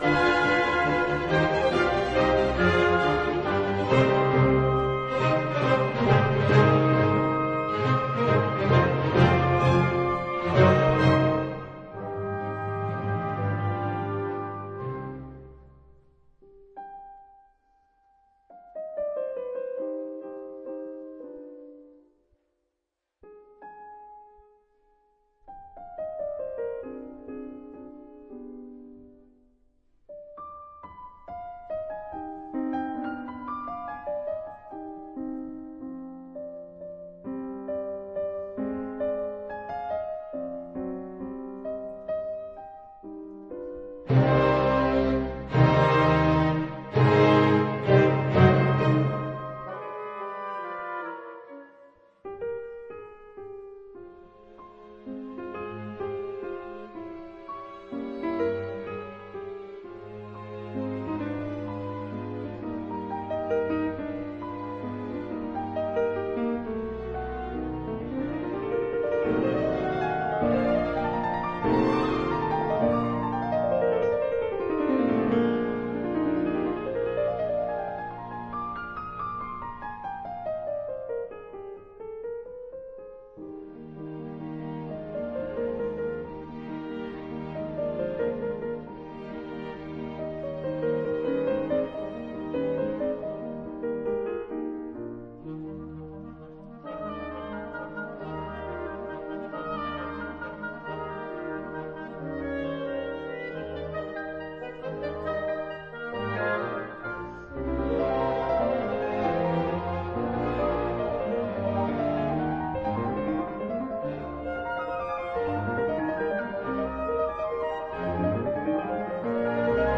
雖然很樸實，也不太像莫札特。